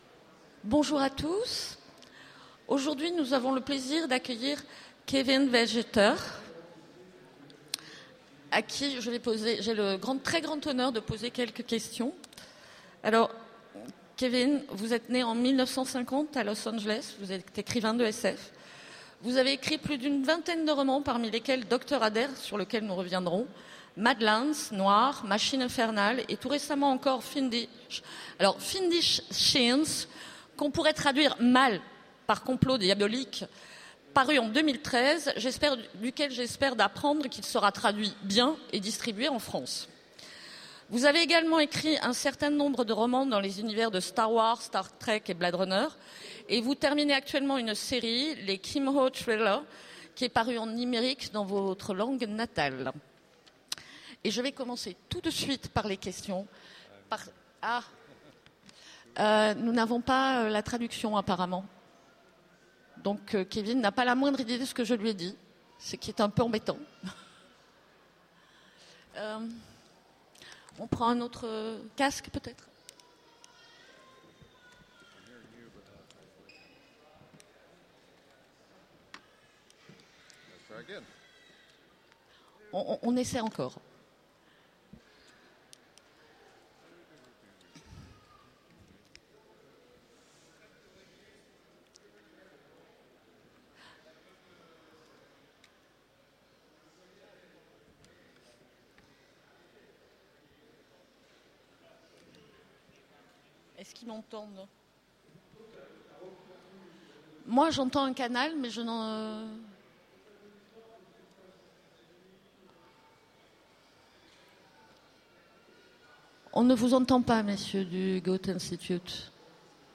Utopiales 2014 : Rencontre avec K. W. Jeter